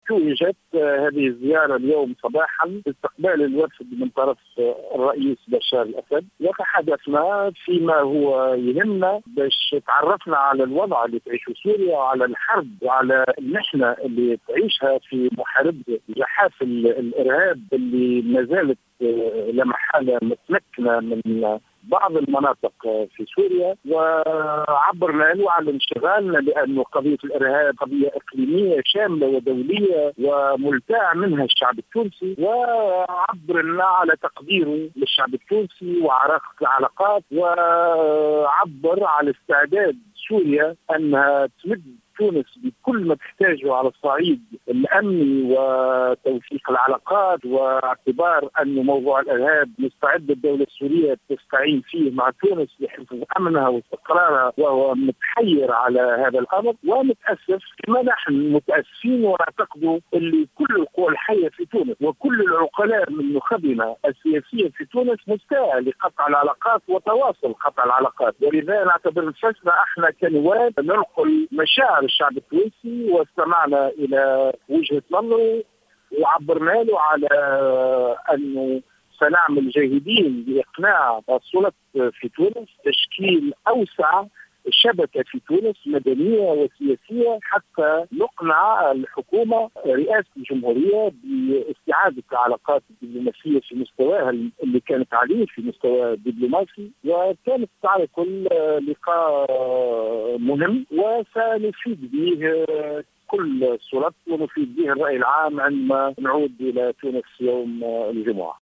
وقال النائب خميس قسيلة احد أعضاء الوفد، في تصريح للجوهرة اف ام، إن اللقاء مع الأسد تمحور حول الوضع في سوريا والحرب على الإرهاب، الذي مازال يسيطر إلى الآن على عدة مناطق سورية.